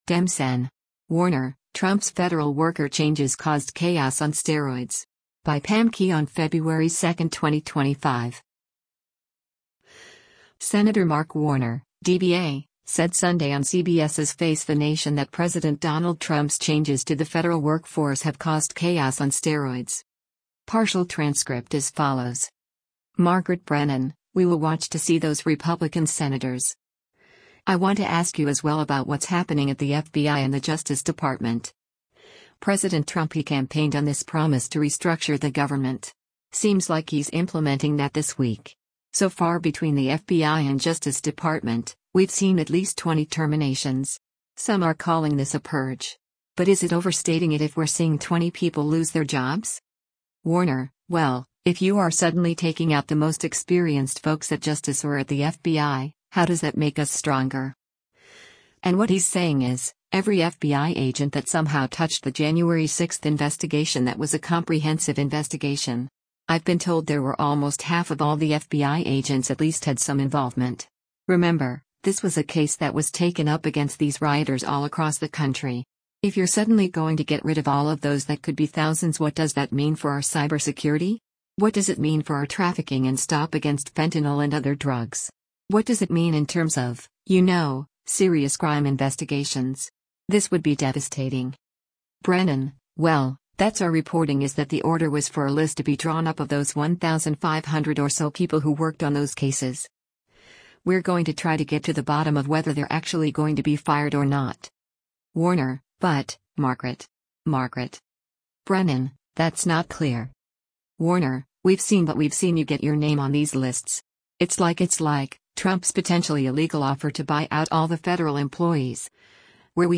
Senator Mark Warner (D-VA) said Sunday on CBS’s “Face the Nation” that President Donald Trump’s changes to the federal work force have caused “chaos on steroids.”